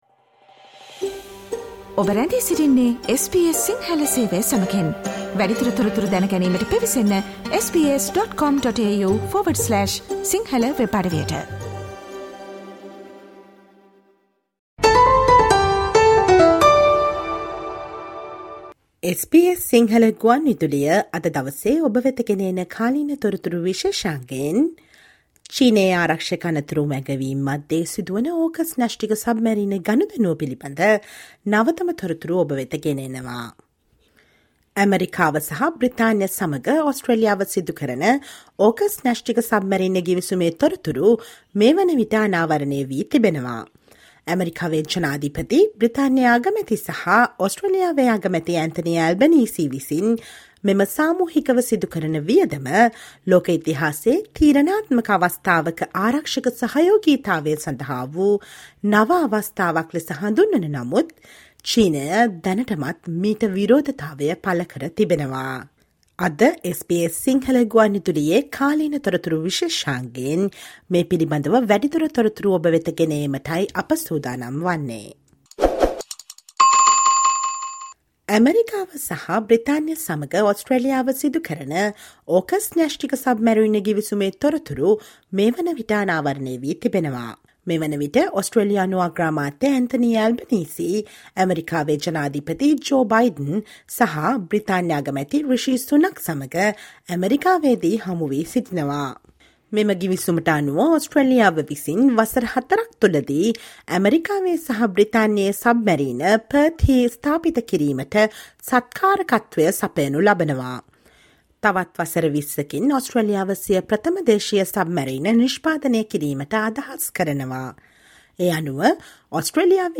Listen to the SBS Sinhala radio current affair feature on the newest updates of the AUKUS nuclear submarine deal with the United States and United Kingdom in which China has already hit back.